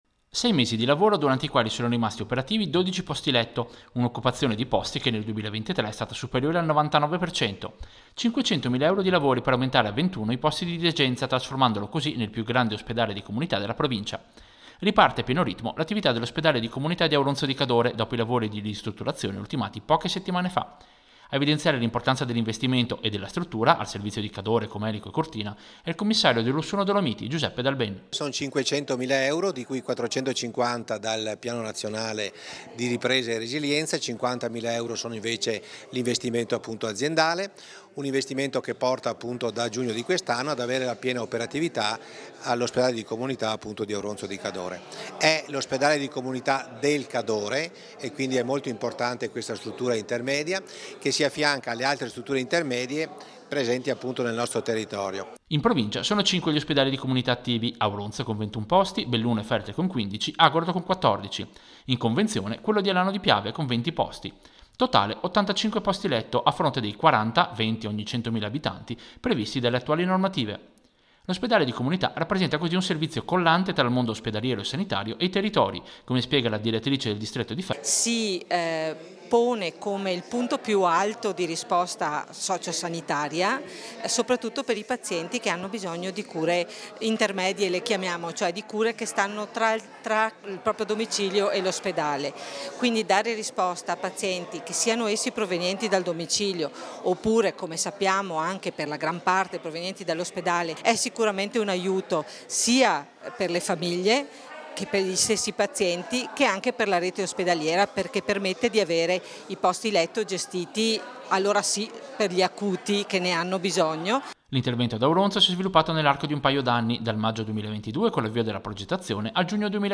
Servizio-Ospedale-di-comunita-Auronzo.mp3